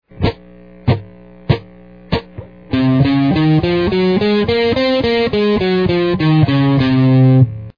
Scales and Modes on the Guitar
C-Locrean.mp3